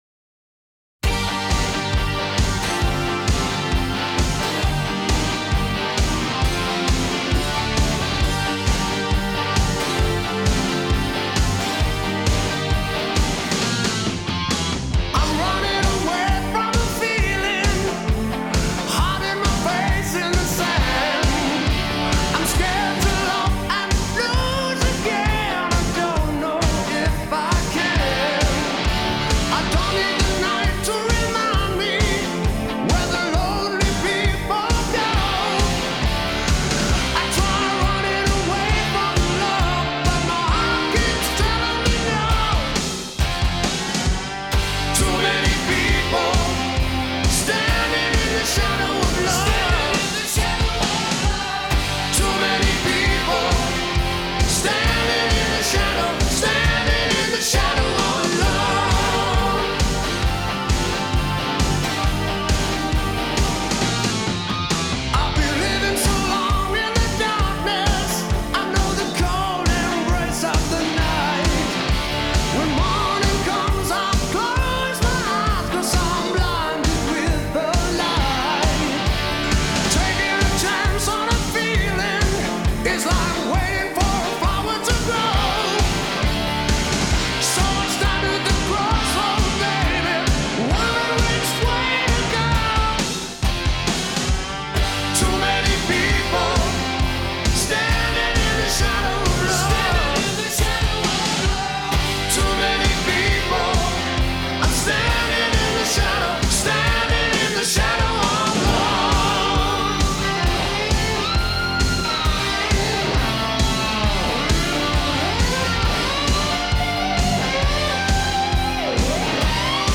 Жанр: Хард-рок